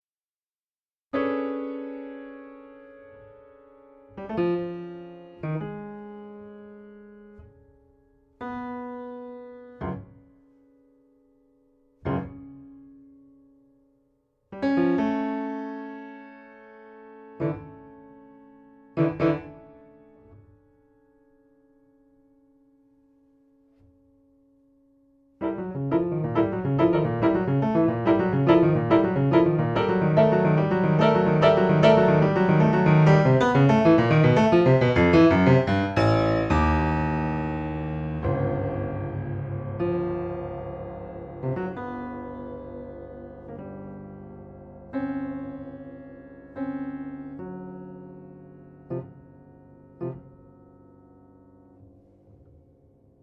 per pianoforte solo